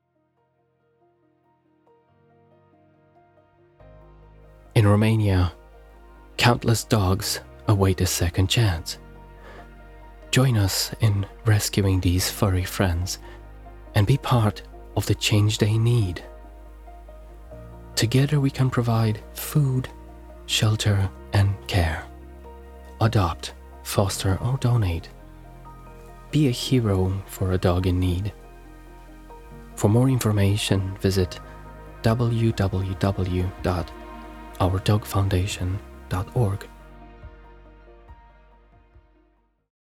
Dog Shelter (American accent)